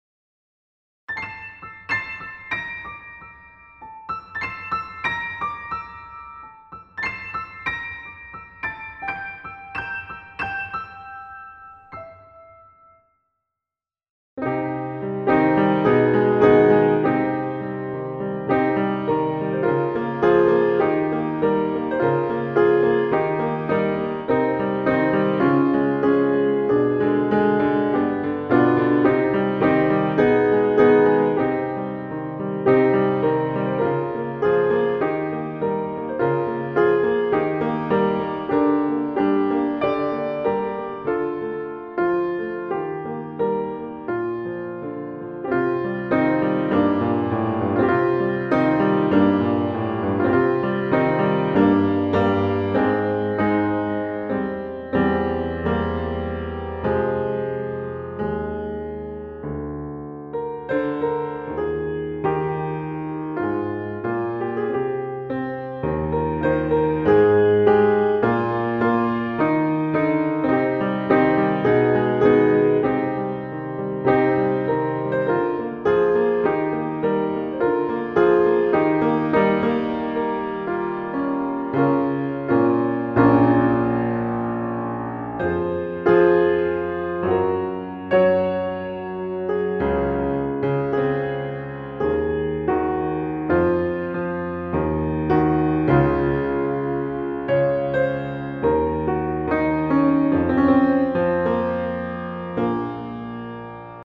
Ah non dsl, je joue sans notes :o